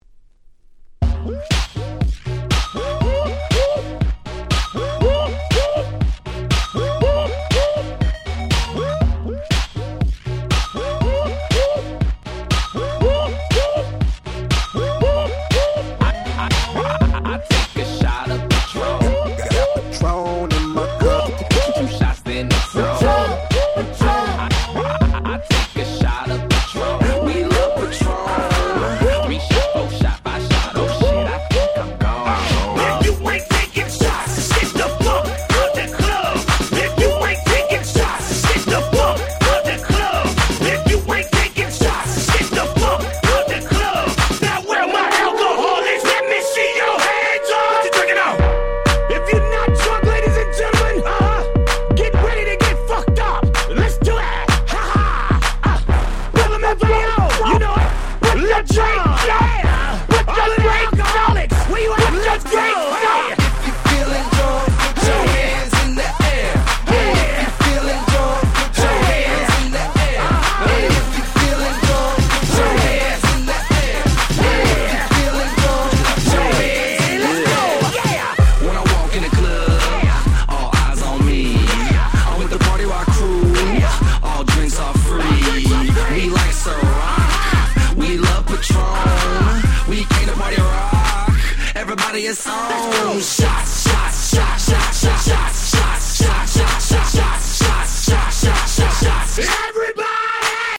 DJがPlay中にBPMを変えるのに超便利なトランジション物を全6曲収録しためちゃ使える1枚！！
(120BPM- 128BPM)